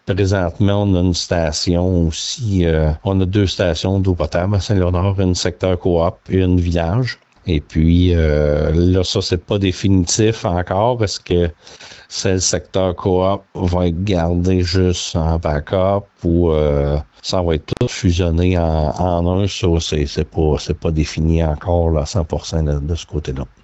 Parmi celles-ci, savoir ce qu’elle fera avec ses deux usines actuelles comme l’a expliqué le maire, Laurent Marcotte.